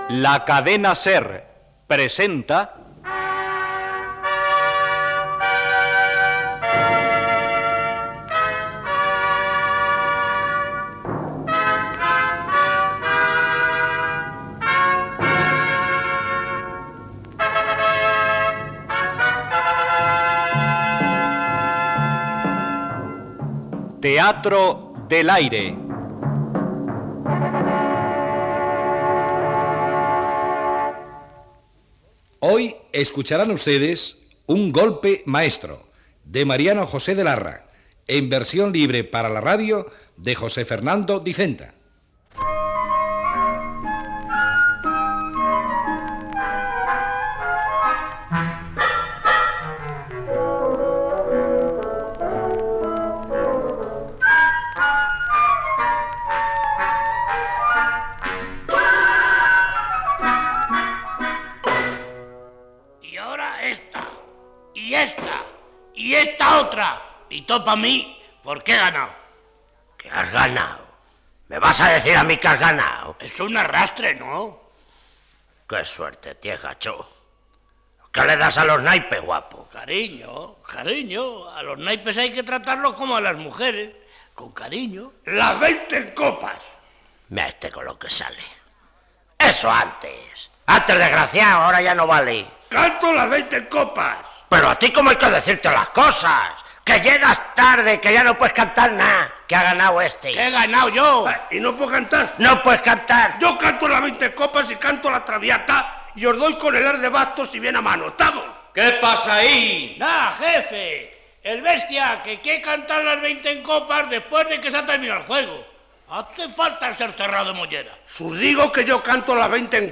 Careta del programa, els personatges estan jugant una partida de cartes
Ficció